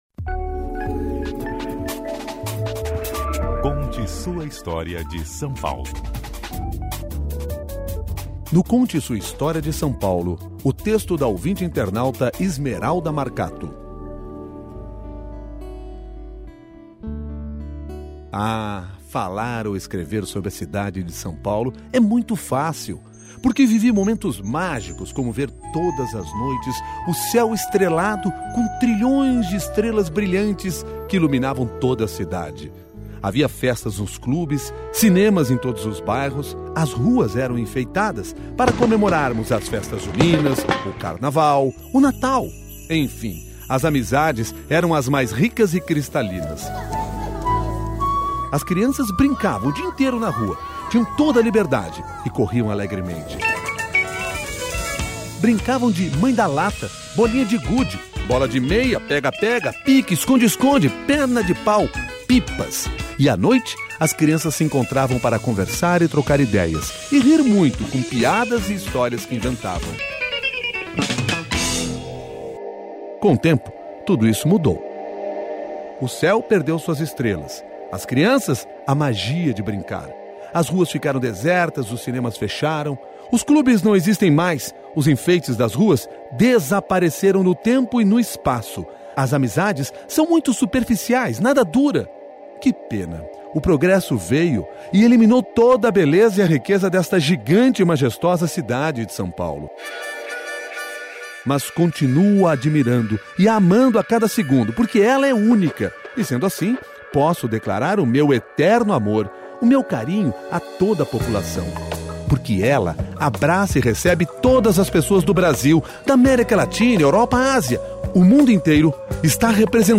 Ouvinte-internauta